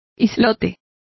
Complete with pronunciation of the translation of islet.